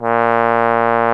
Index of /90_sSampleCDs/Roland LCDP12 Solo Brass/BRS_Trombone/BRS_Tenor Bone 2